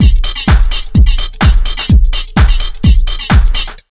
127BEAT4 4-L.wav